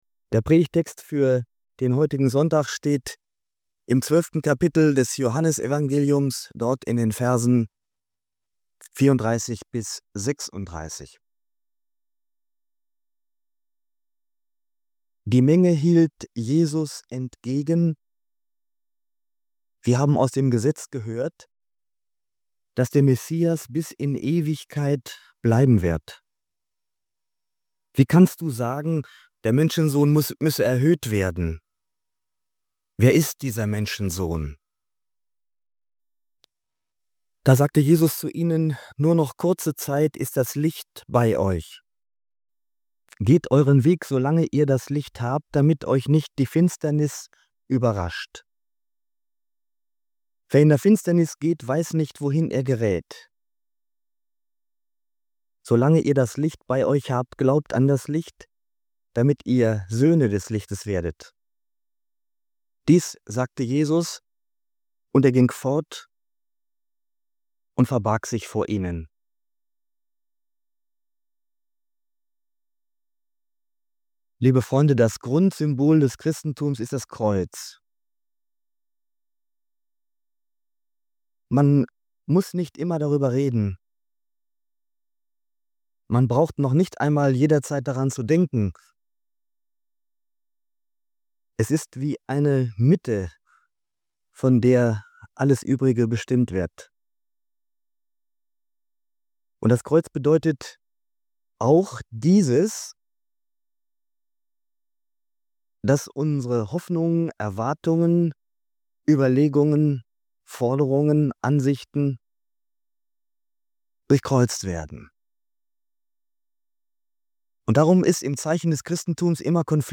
Beschreibung vor 4 Tagen Die Predigt stellt einen zentralen Konflikt des christlichen Glaubens heraus: Unsere Erwartungen an einen starken, erfolgreichen Messias treffen auf den gekreuzigten Christus.